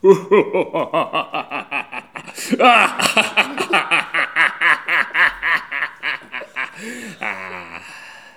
Les sons ont été découpés en morceaux exploitables. 2017-04-10 17:58:57 +02:00 1.4 MiB Raw History
rire-machiavelique_01.wav